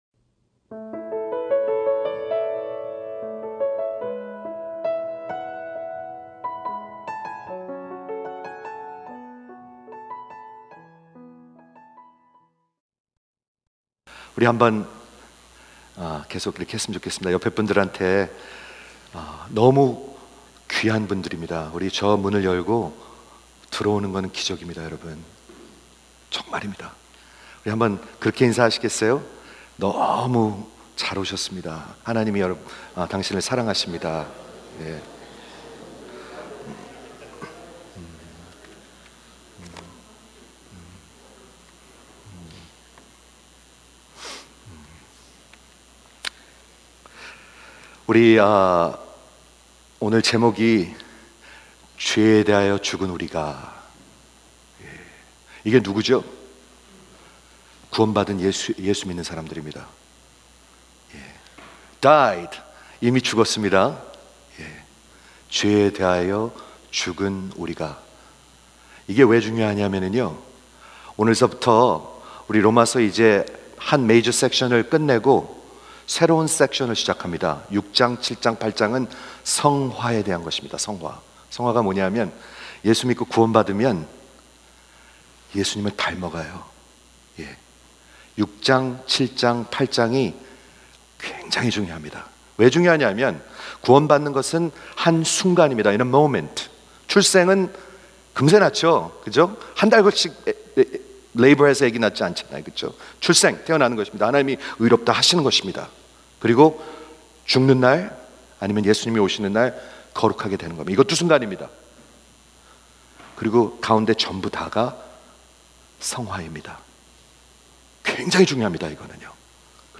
Christianity Expounded (19) 로마서 설교 시리즈: 복음 – 죄에 대해 죽음 (성화 1) | 로마서 6:1-14